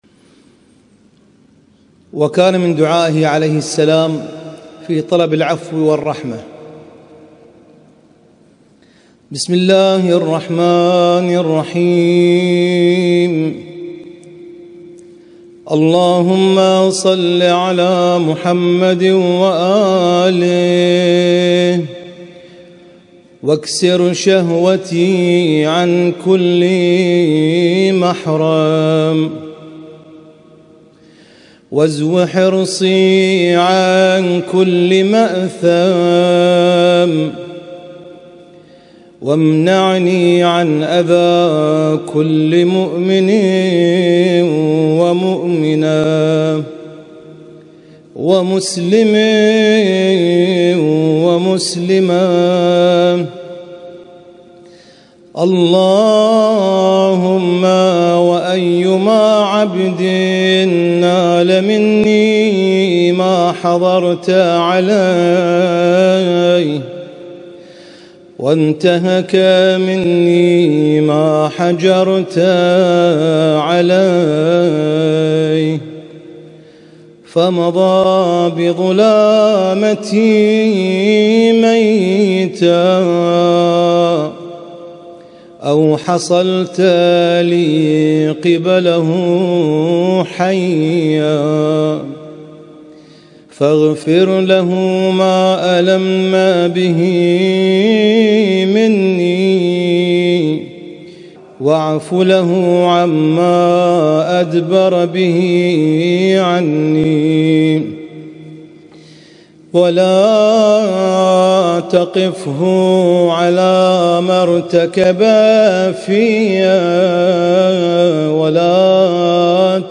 اسم التصنيف: المـكتبة الصــوتيه >> الصحيفة السجادية >> الادعية السجادية